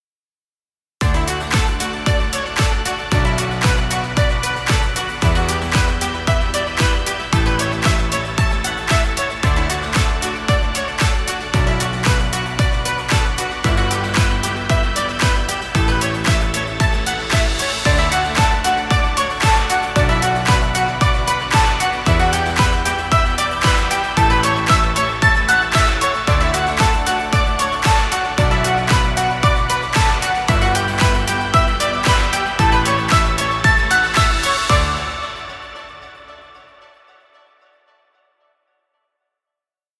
Happy fun music.